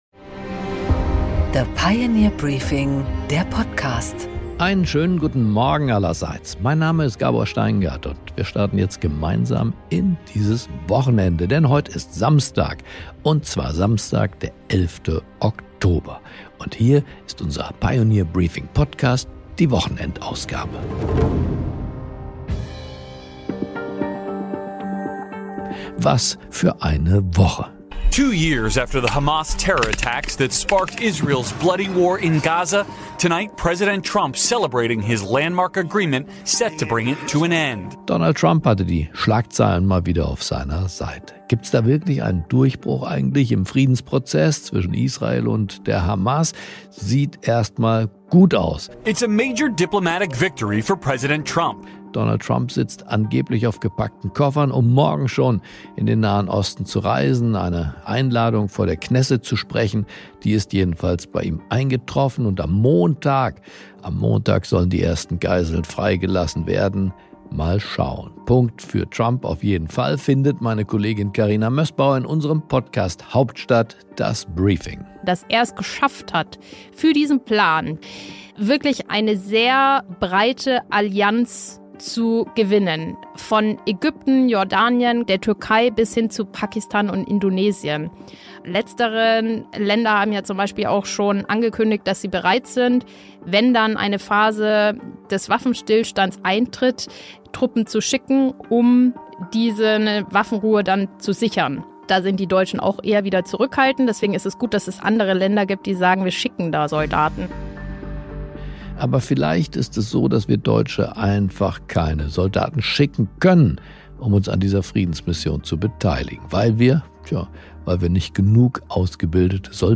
Gabor Steingart präsentiert die Pioneer Briefing Weekend Edition